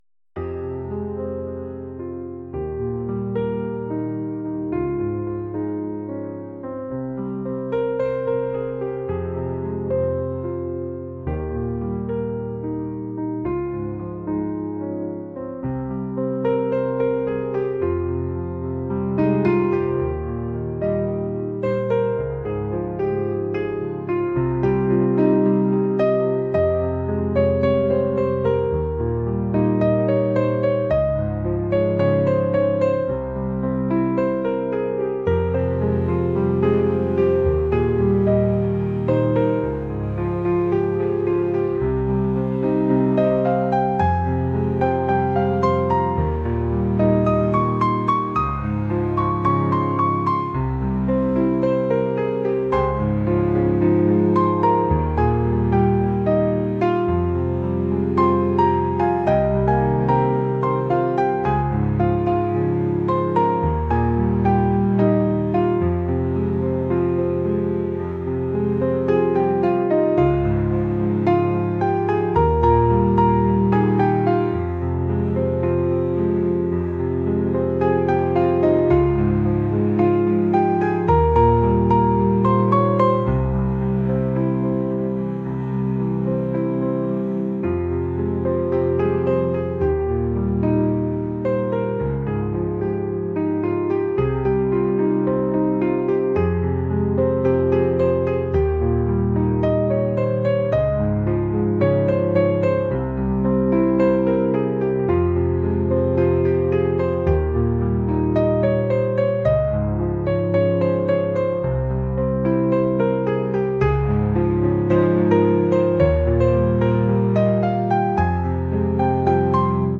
acoustic | classical | pop